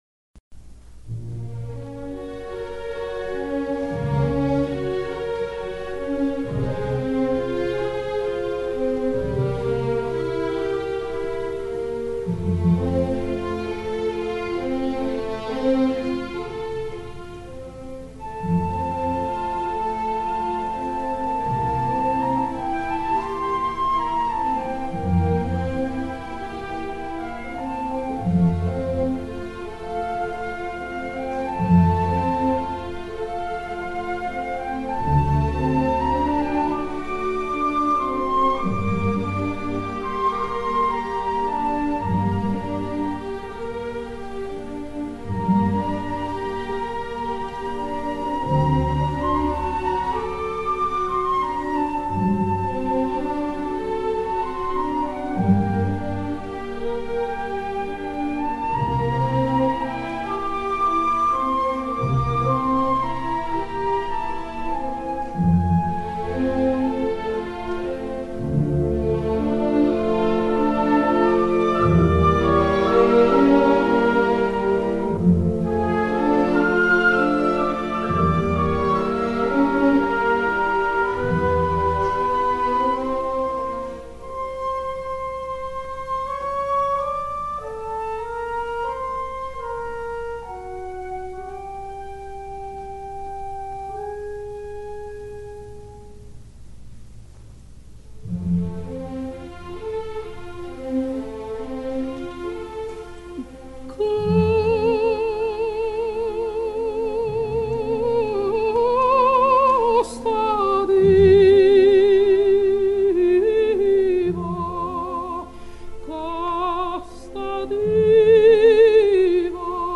Norma, Act I, sc. i, aria “Casta Diva” (1831); Disc 1, #10